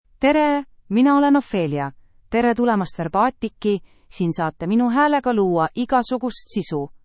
OpheliaFemale Estonian AI voice
Ophelia is a female AI voice for Estonian (Estonia).
Voice sample
Listen to Ophelia's female Estonian voice.
Ophelia delivers clear pronunciation with authentic Estonia Estonian intonation, making your content sound professionally produced.